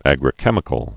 (ăgrĭ-kĕmĭ-kəl)